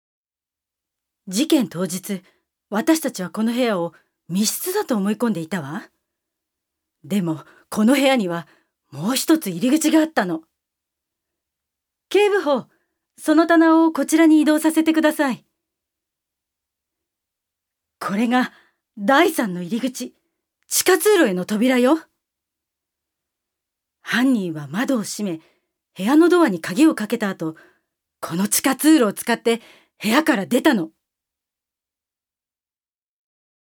ジュニア：女性
セリフ１